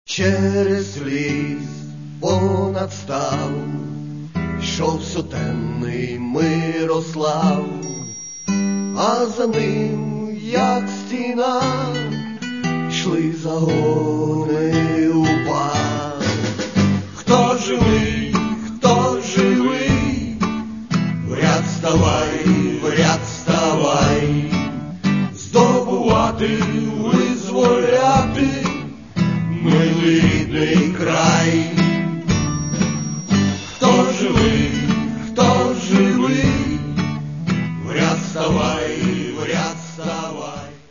Каталог -> Рок та альтернатива -> Поетичний рок